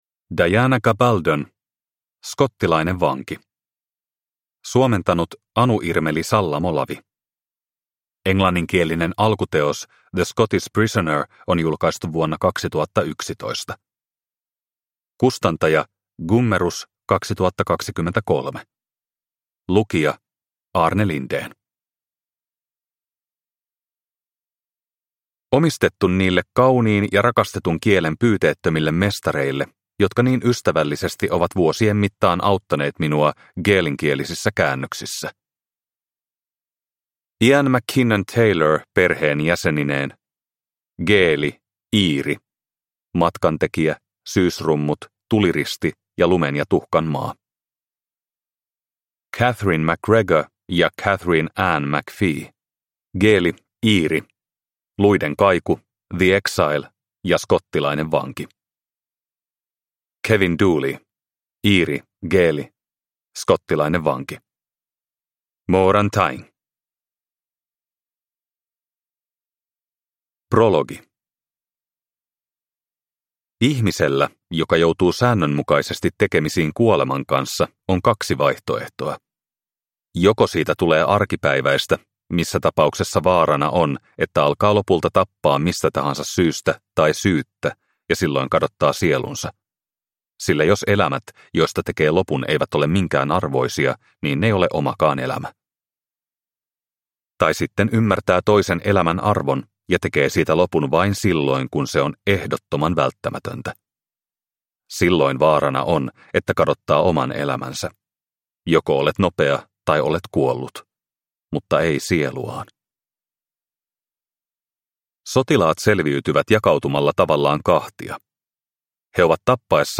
Skottilainen vanki – Ljudbok – Laddas ner